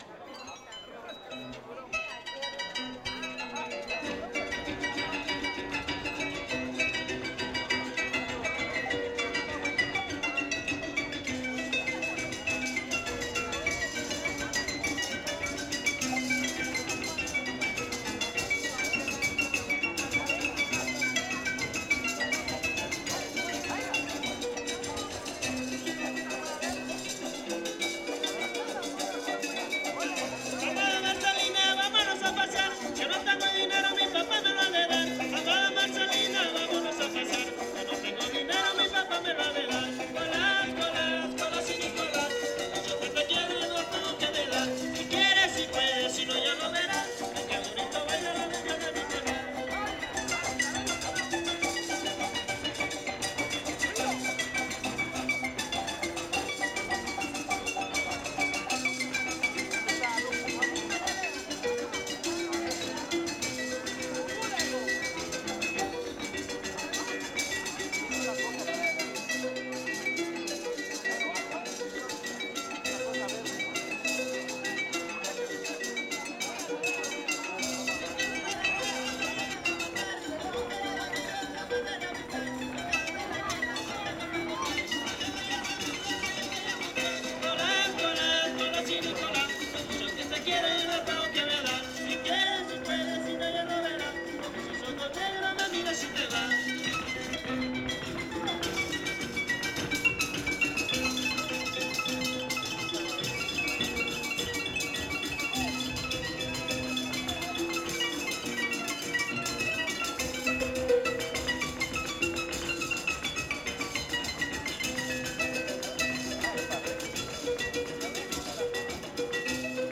Concurso Estatal de Fandango